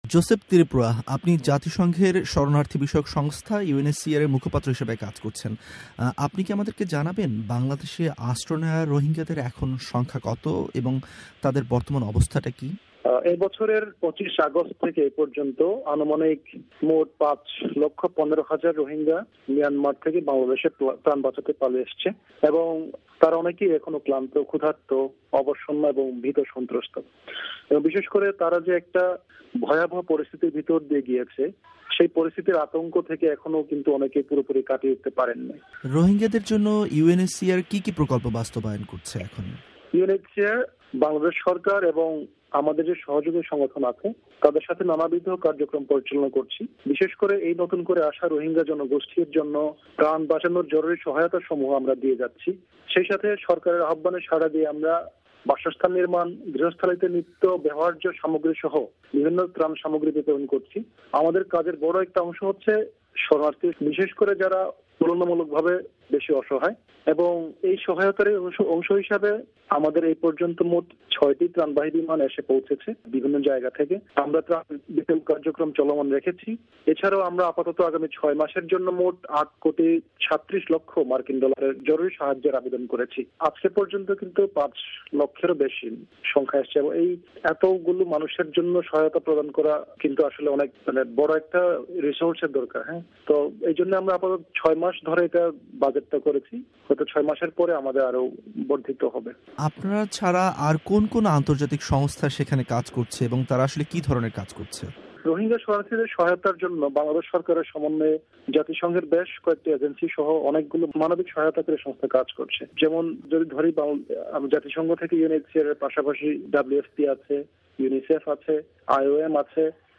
একান্ত সাক্ষাৎকারে